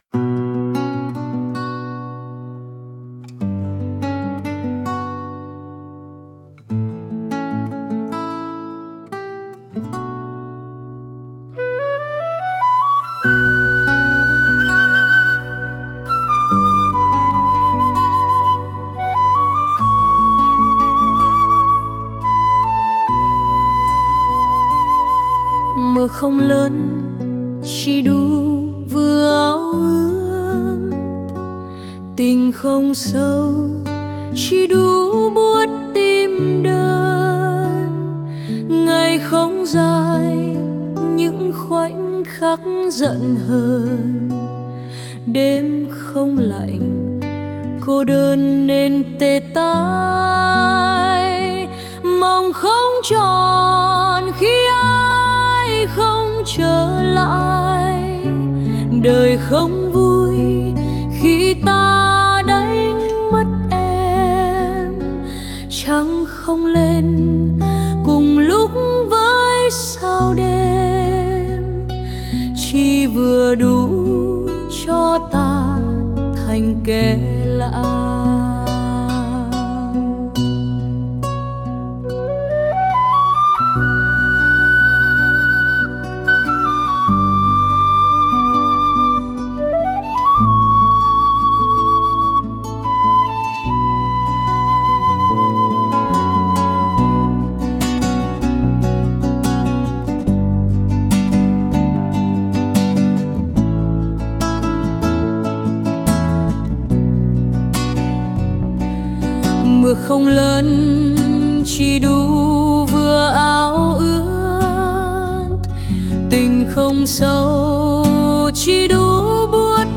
MP3-SÁCH NÓI AUDIO